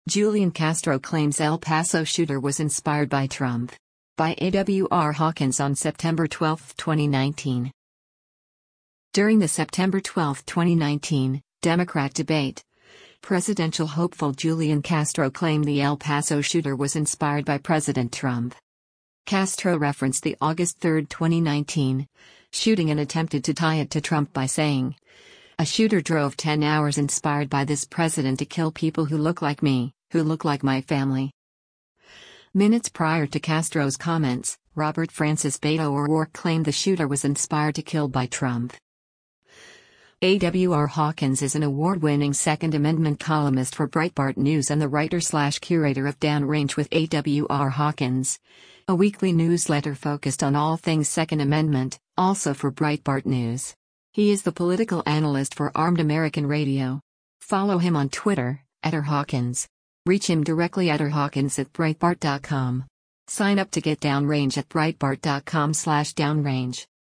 During the September 12, 2019, Democrat debate, presidential hopeful Julian Castro claimed the El Paso shooter was “inspired” by President Trump.